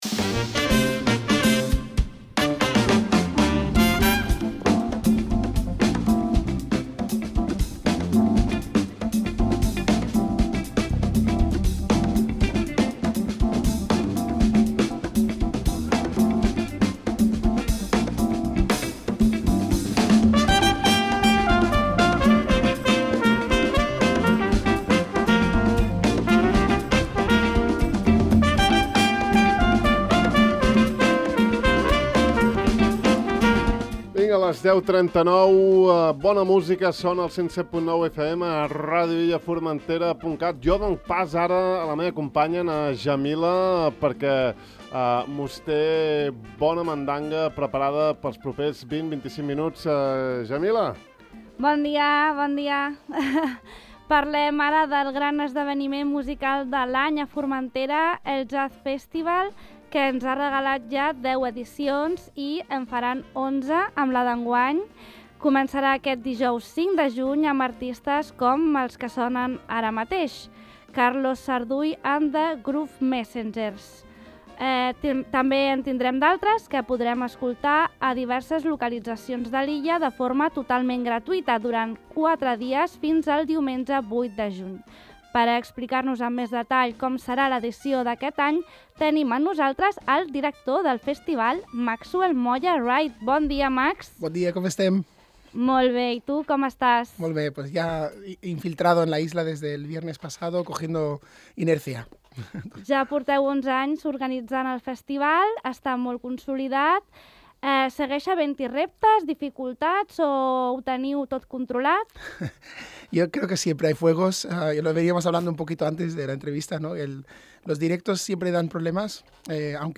A continuació, us deixem l’entrevista sencera: